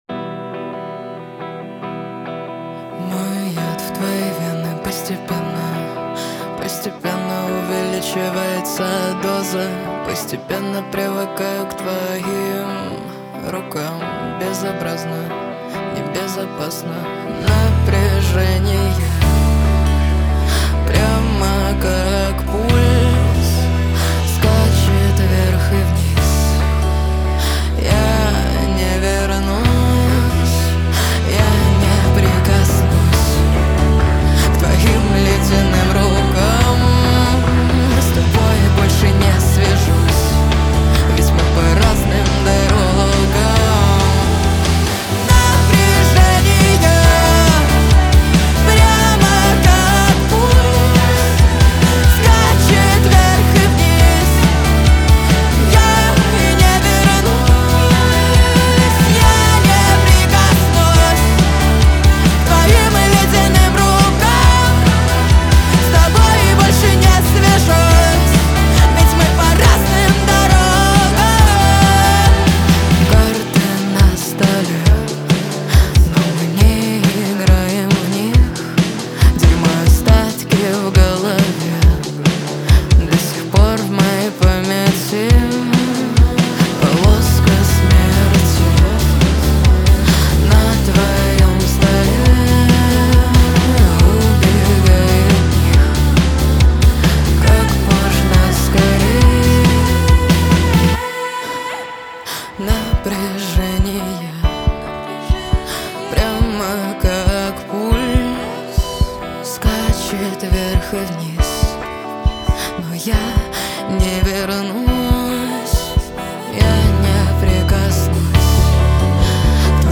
Качество: 320 kbps, stereo
Рок музыка 2025